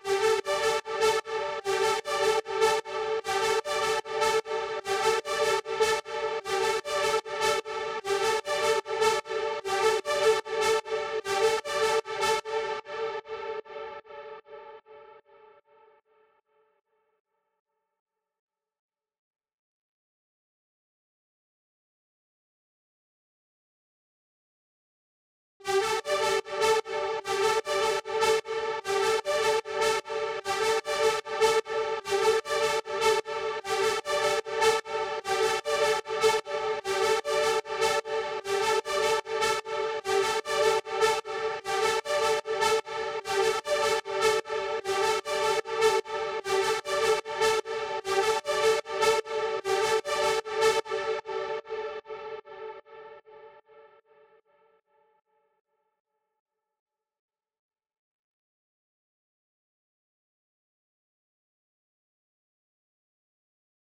🔹 52 Premium Serum Presets built for synthwave, retro pop, and nostalgic melodic house.
Lush Keys & Warm Pads – Layered textures that glide through the mix